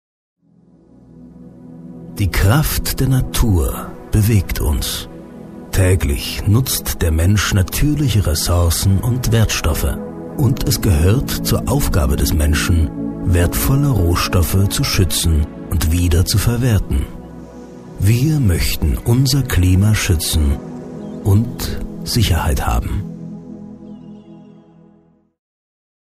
Kein Dialekt
Sprechprobe: Industrie (Muttersprache):